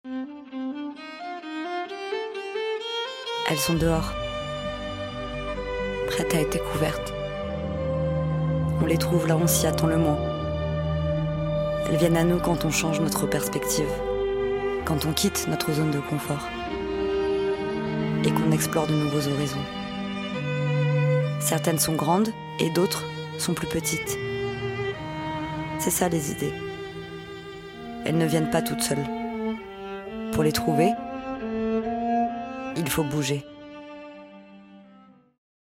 PUB 1